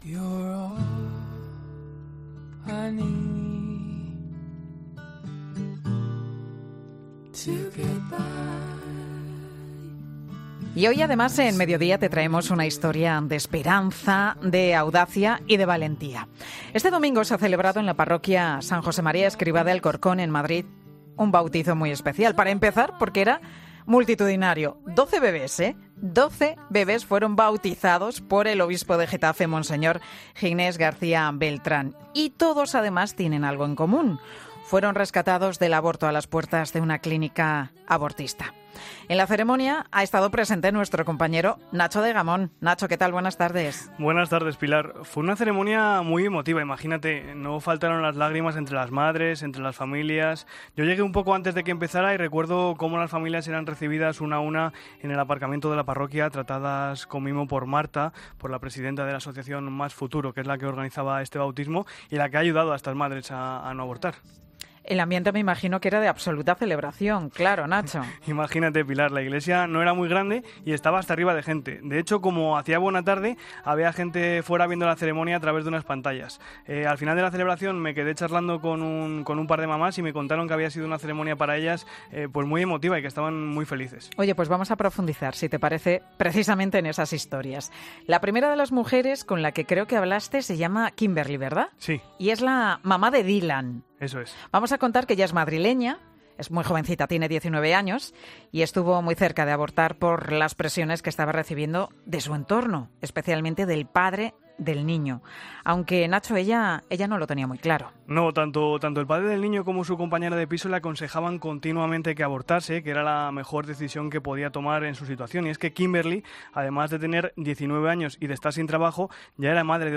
COPE es testigo del bautismo de 12 bebés salvados a las puertas de una clínica abortista gracias a la iniciativa de unos jóvenes